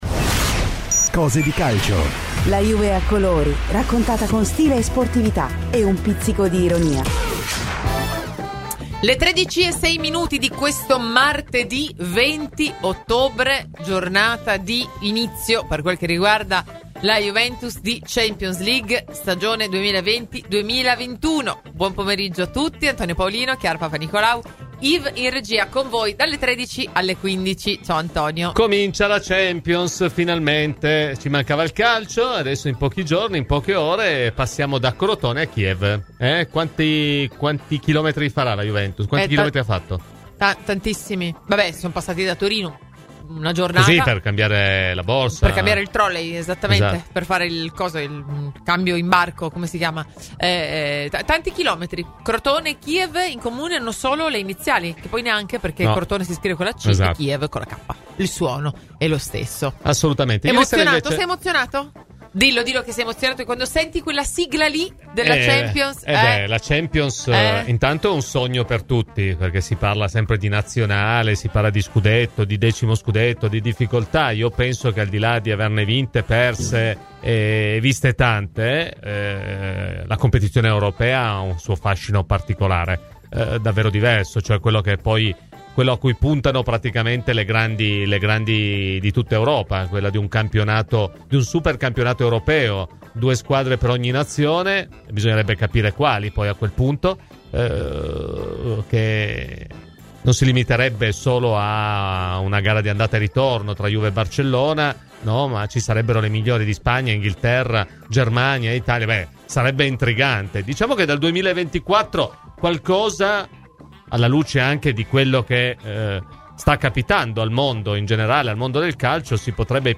Cicca sul podcast in calce per la trasmissione integrale.
© registrazione di Radio Bianconera